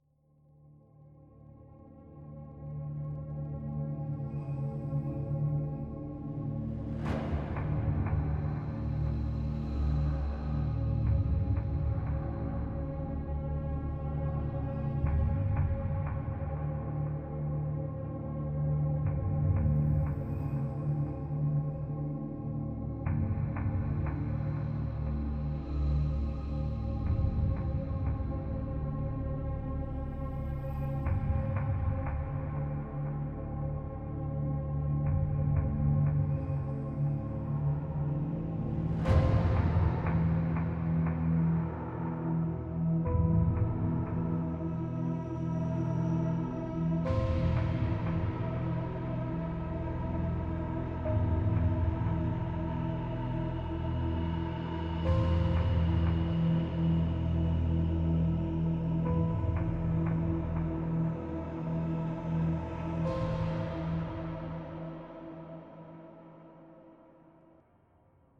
airplanechillbackground.mp3